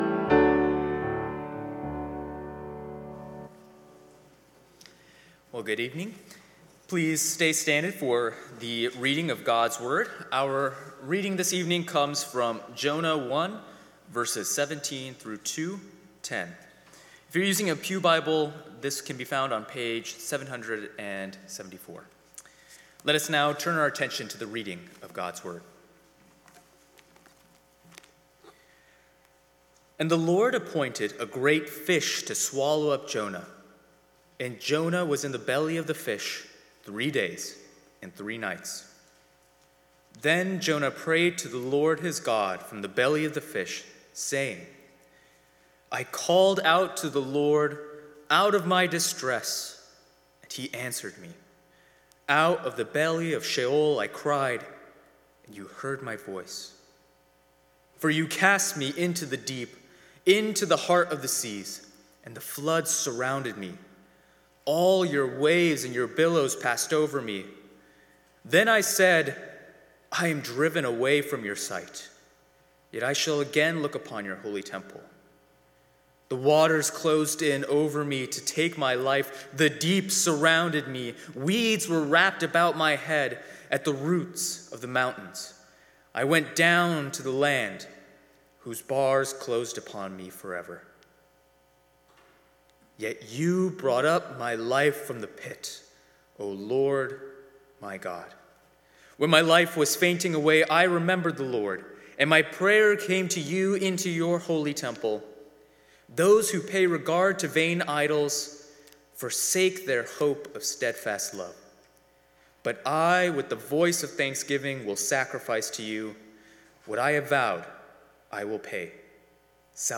Sermons | New Life Presbyterian Church of La Mesa